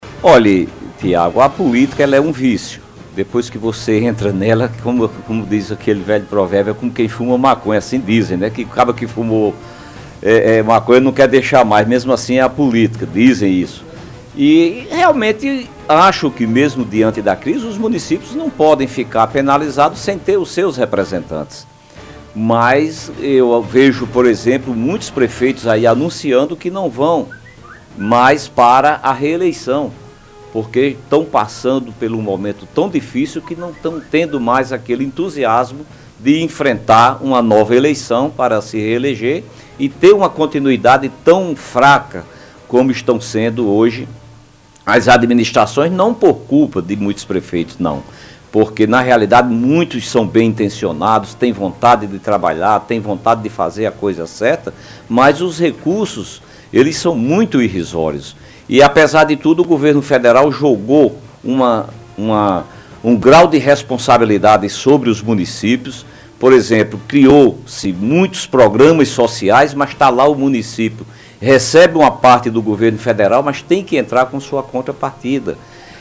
O deputado estadual Branco Mendes, esteve na tarde desta sexta-feira (12), no programa Debate Sem Censura, da rádio Sanhauá. Ele falou sobre a crise que o Brasil vem enfrentando e destacou o quanto os municípios paraibanos estão sendo afetados.